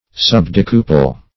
Subdecuple \Sub*dec"u*ple\, a. Containing one part of ten.